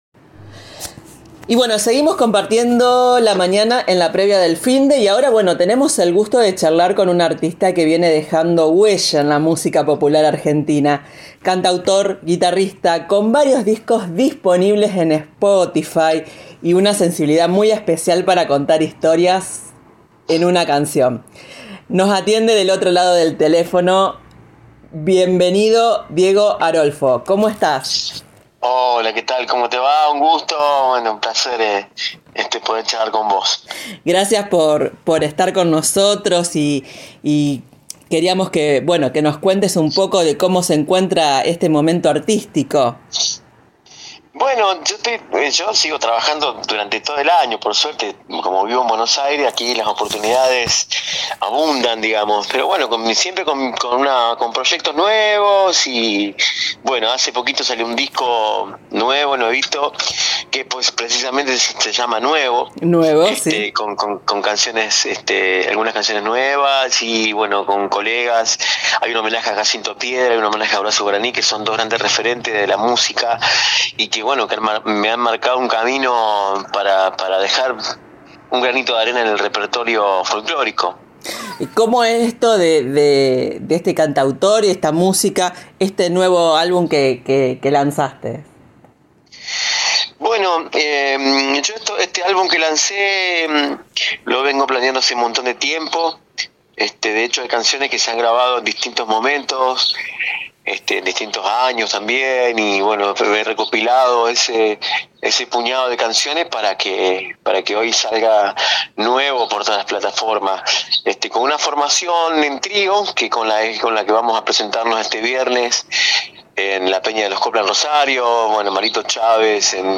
Una charla cálida sobre música, raíces, sueños y caminos recorridos.